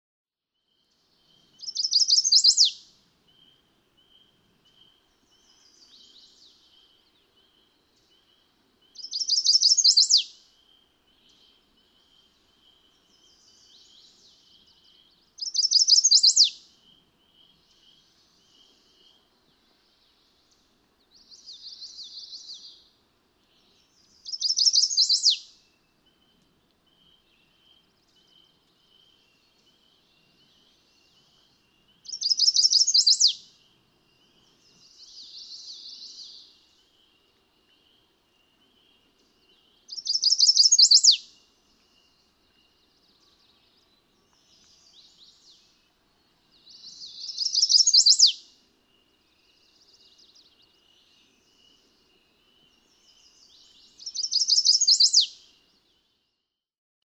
Chestnut-sided warbler
A common daytime song that is used by many males; daytime songs cannot reliably be used to distinguish individuals.
Savoy Mountain State Forest, Florida, Massachusetts.
572_Chestnut-sided_Warbler.mp3